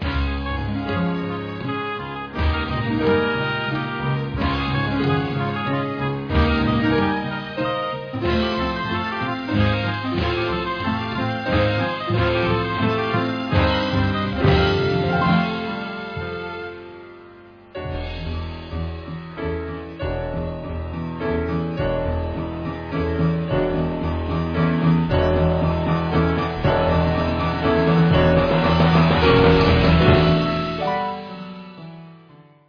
* instrumental